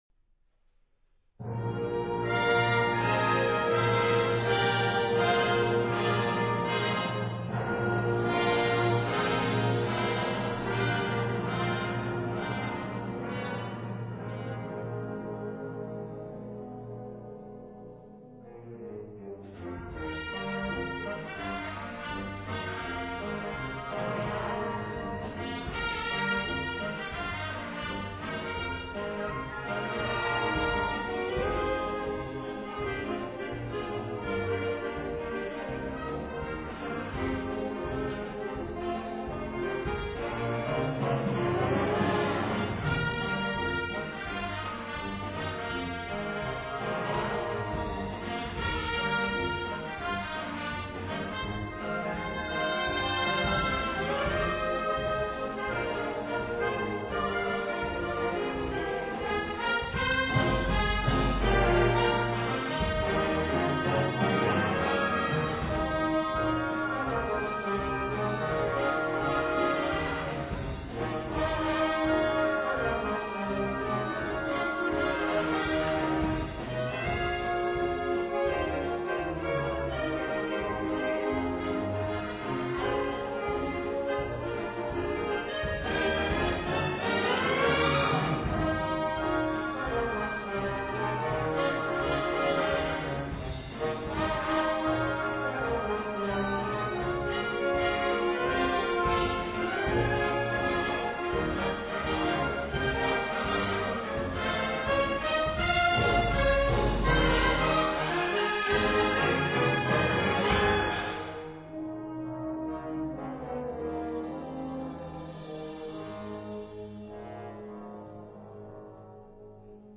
Brano da concerto
MUSICA PER BANDA